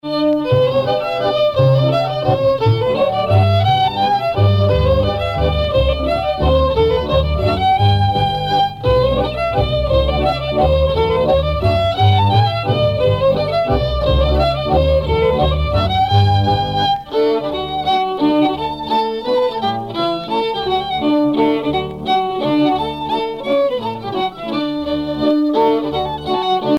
danse : mazurka-valse
Pièce musicale éditée